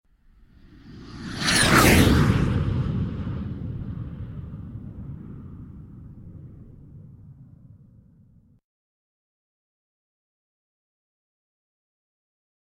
3D Tones